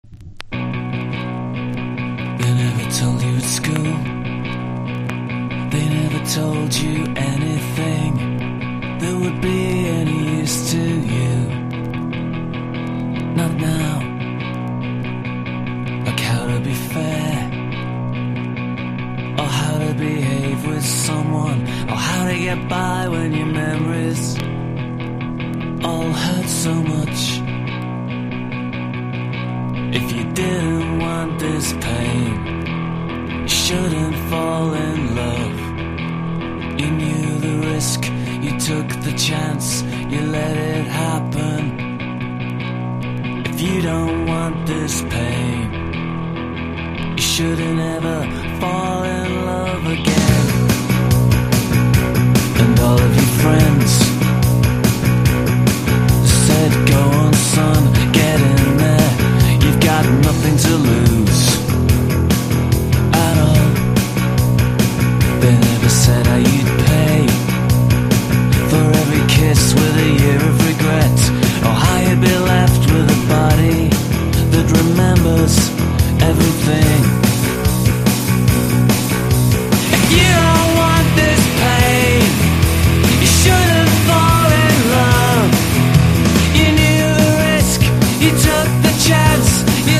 NEO ACOUSTIC / GUITAR POP